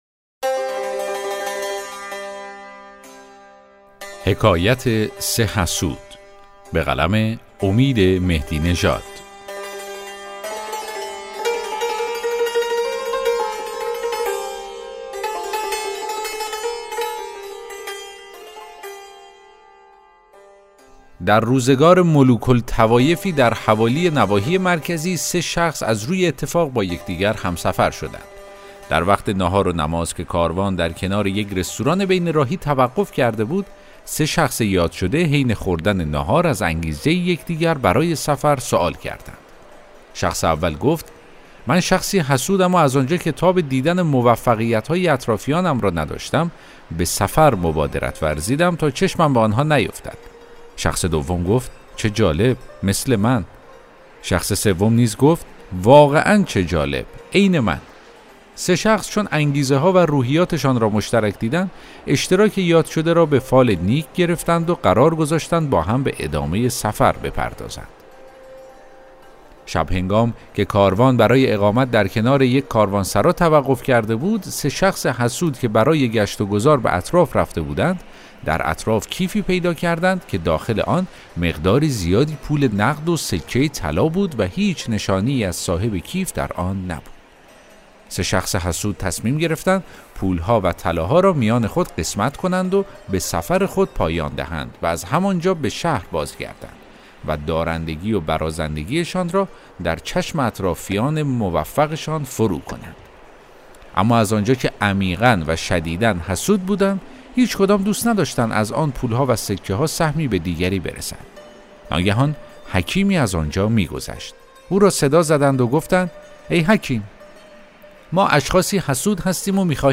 داستان صوتی: حکایت ۳  حسود